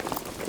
tac_gear_27.ogg